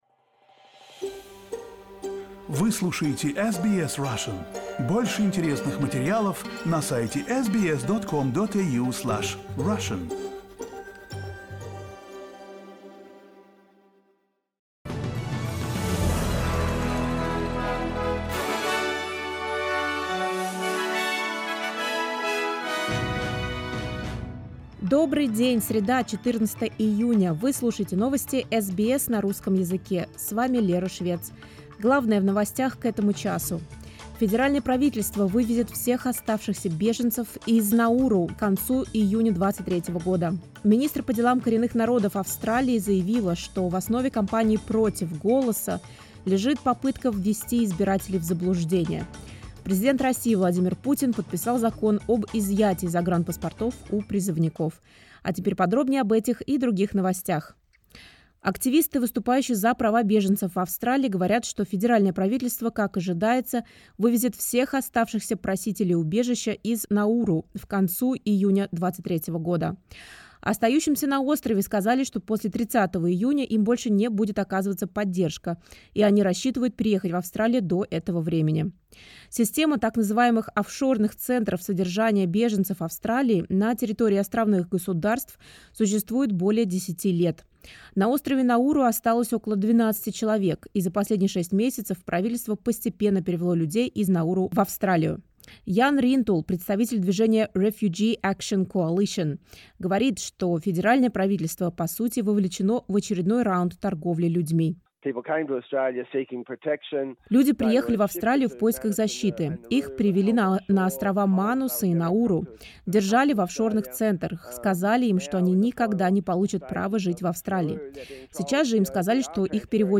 SBS news in Russian —14.06.2023